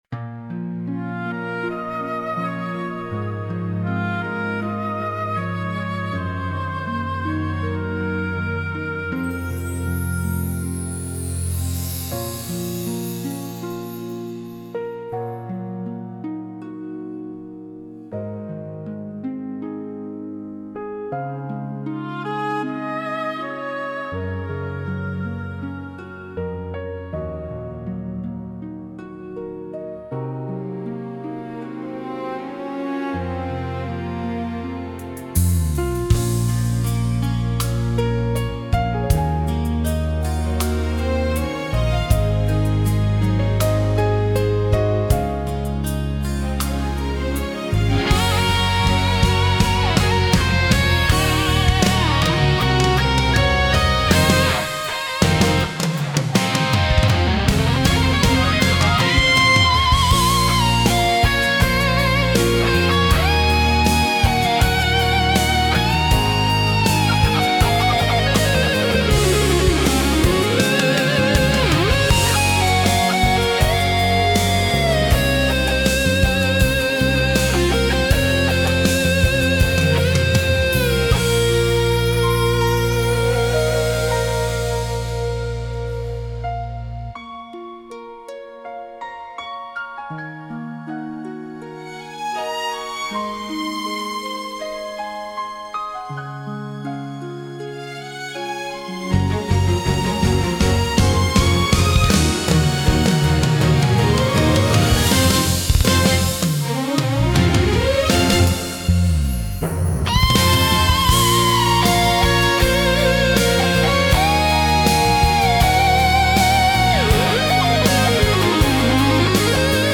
ジャンル バラード